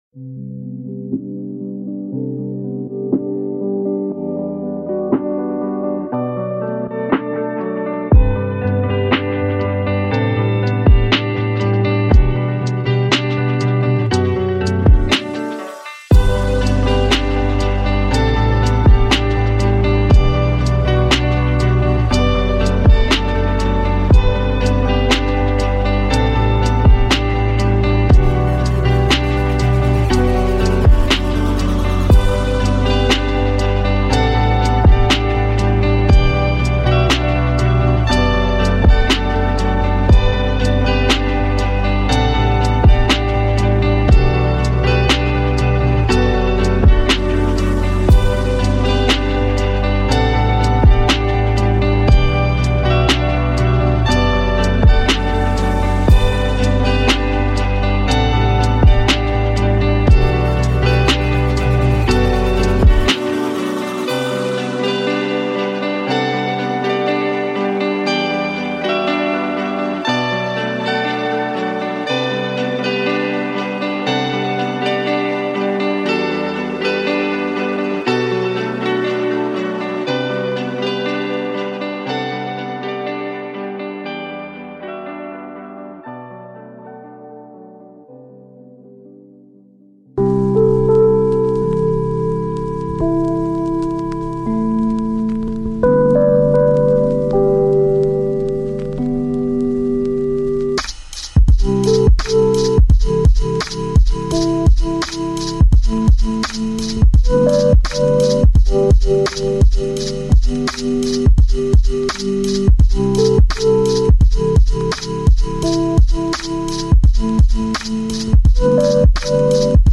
Classique Calme : Focus Profond